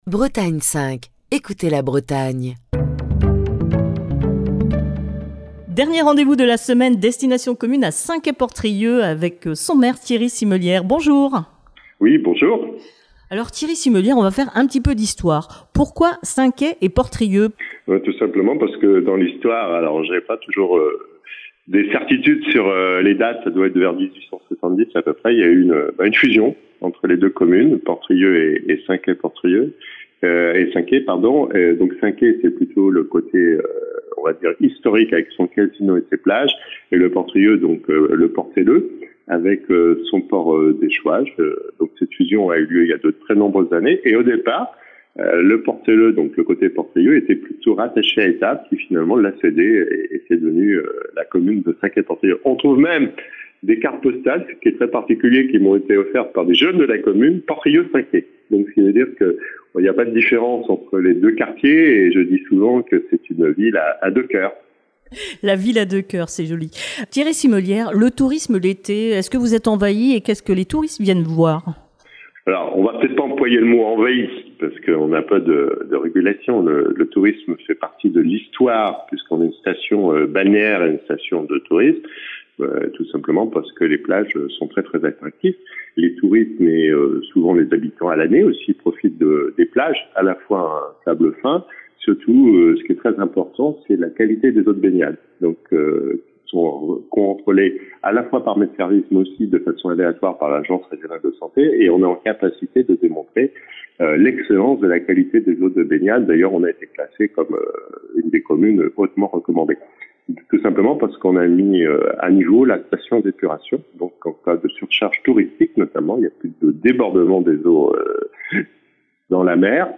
est au téléphone avec Thierry Simelière, le maire de Saint-Quay-Portrieux.